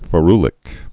(fə-rlĭk)